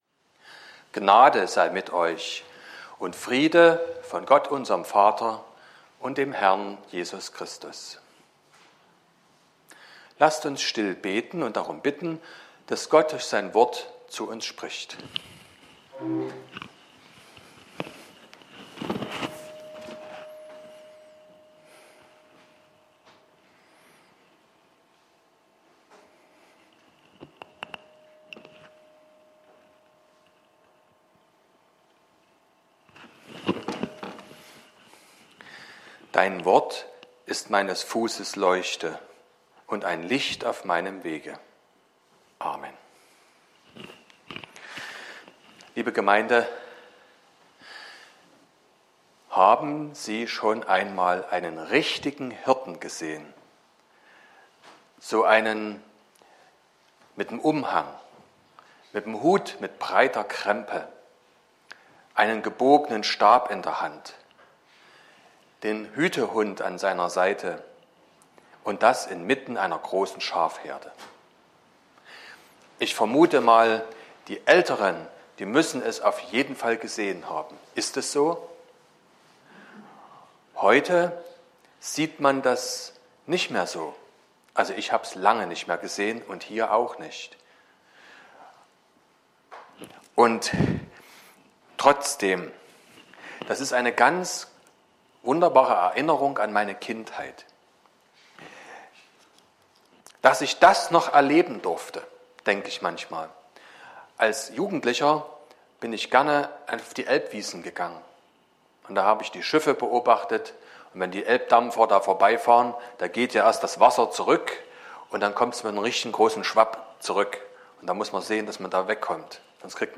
06.02.2022 – Gottesdienst
Predigt (Audio): 2022-02-06_Ich_bin_der_gute_Hirte__Predigtreihe_2022__Thema_5_.mp3 (24,6 MB)